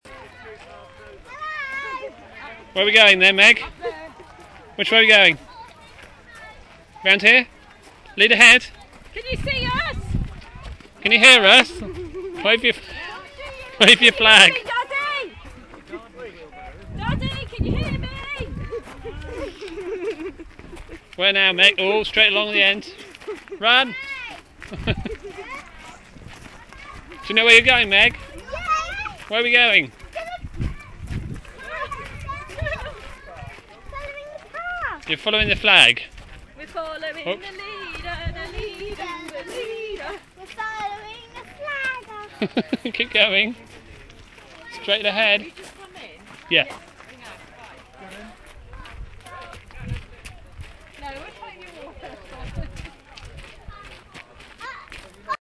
The sound of an open air maze
50920-the-sound-of-an-open-air-maze.mp3